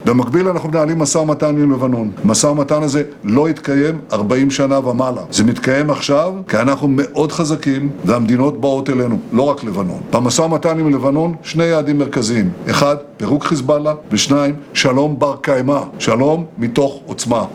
Izraelski premijer Benjamin Netnyahu: